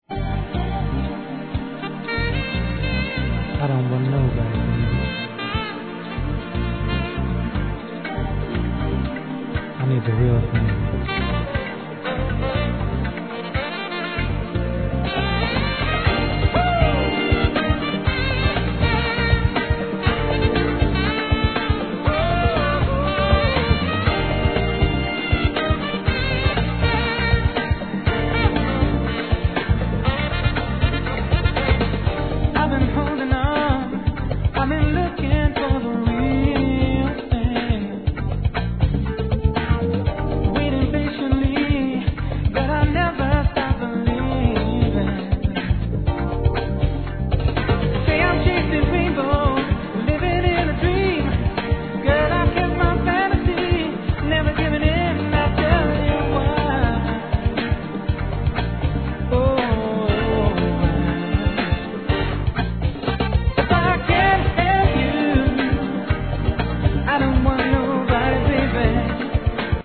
哀愁を感じさせるメロディラインと、透明感のあるヴォーカルで